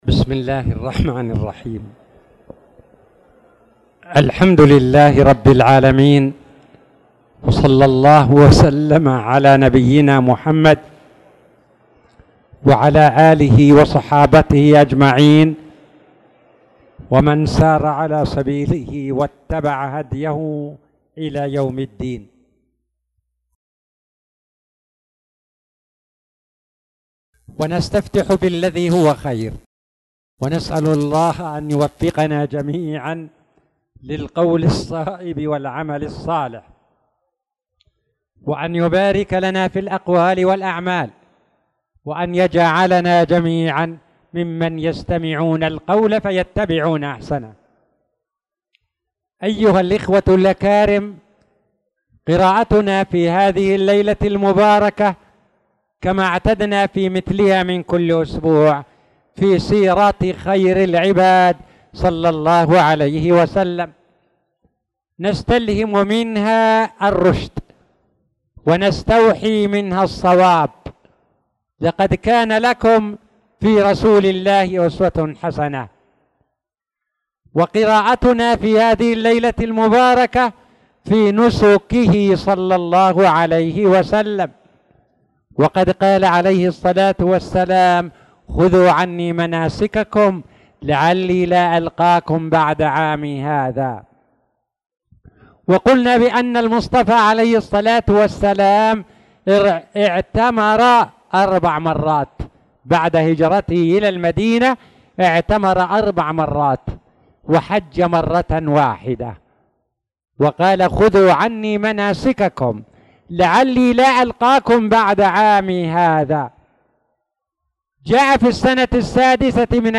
تاريخ النشر ١٩ شعبان ١٤٣٧ هـ المكان: المسجد الحرام الشيخ